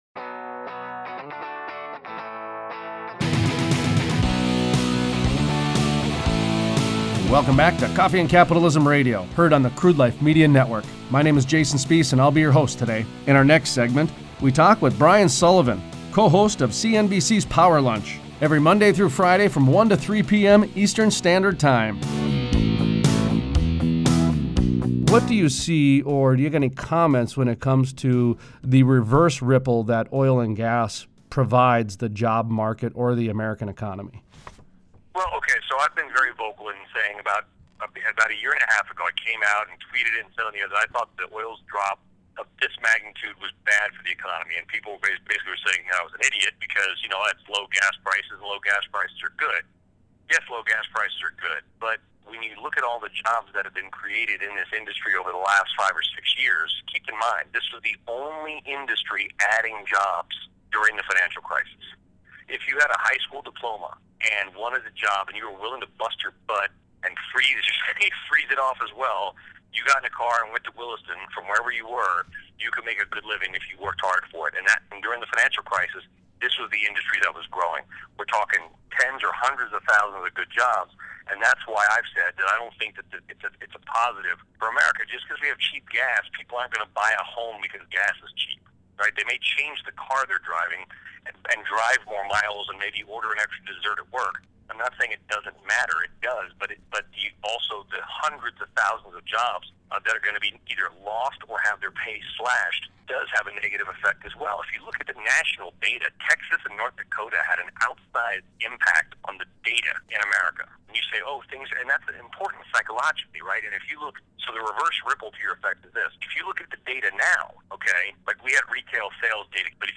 Interviews: Brian Sullivan, host, Power Lunch, CNBC, New York, NY Talks about the Reverse Ripple happening in the energy industry.